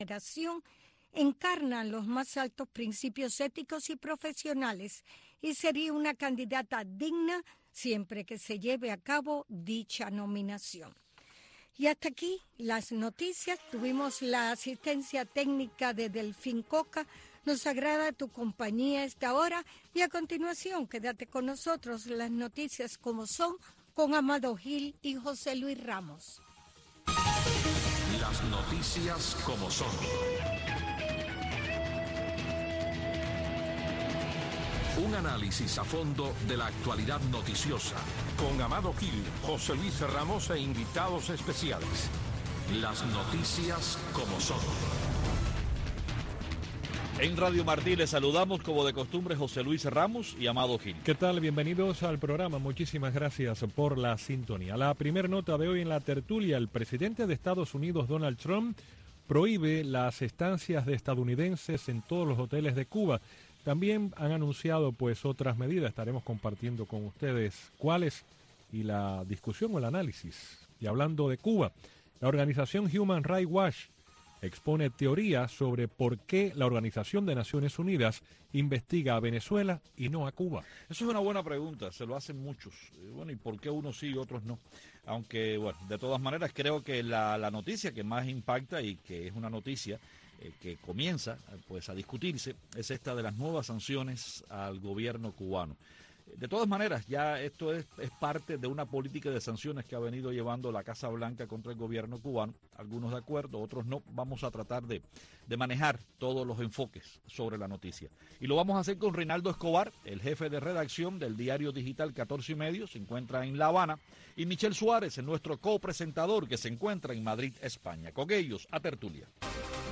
Una discusión dinámica y a fondo de las principales noticias del acontecer diario de Cuba y el mundo, con la conducción de los periodistas